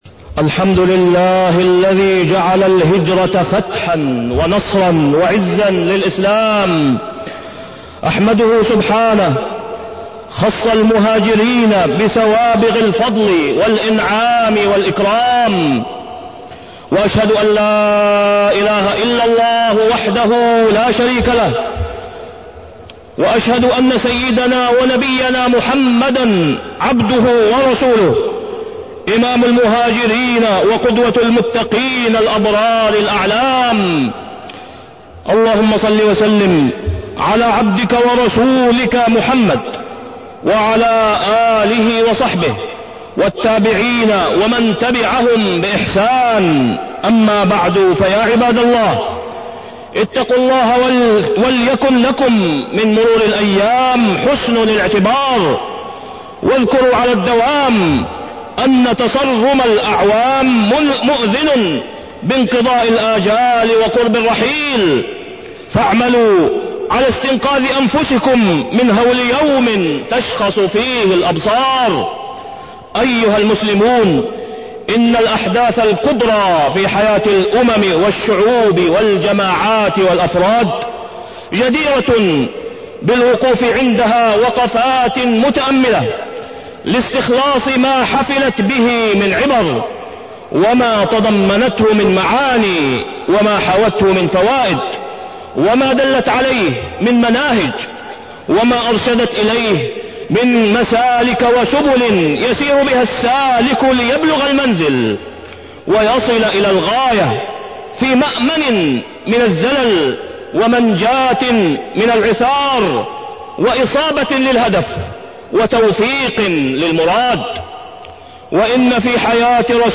تاريخ النشر ٢٧ ذو الحجة ١٤٢٣ هـ المكان: المسجد الحرام الشيخ: فضيلة الشيخ د. أسامة بن عبدالله خياط فضيلة الشيخ د. أسامة بن عبدالله خياط الهجرة النبوية